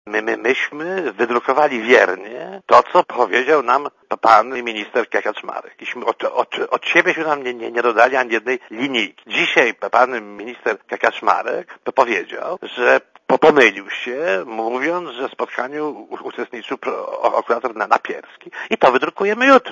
Dla radia Zet mówi Adam Michnik (76 KB)